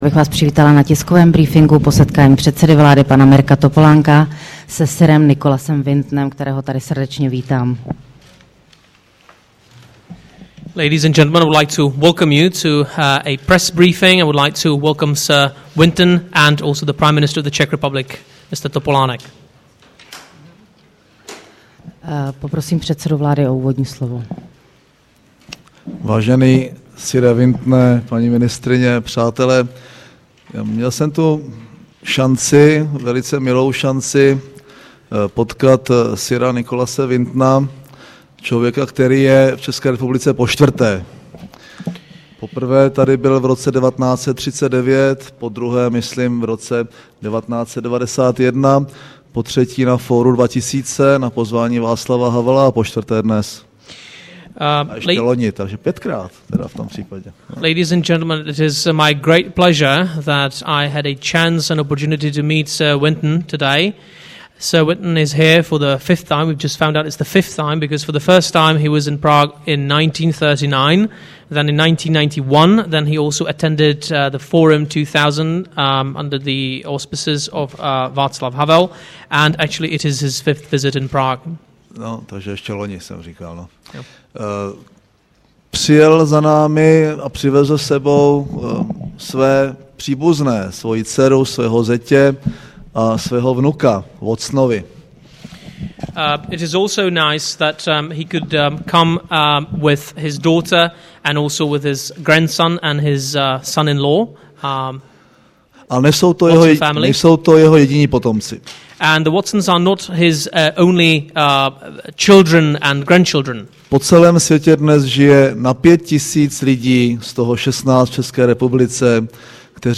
Tisková konference po setkání premiéra Mirka Topolánka se Sirem Nicolasem Wintonem 26. června 2008